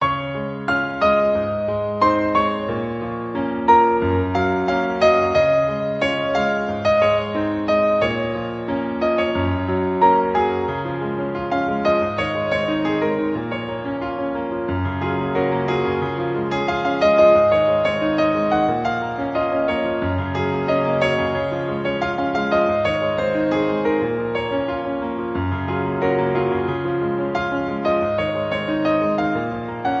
• 🎹 Instrument: Piano Solo
• 🎼 Key: Db major
• 🎶 Genre: Pop
arranged for solo piano.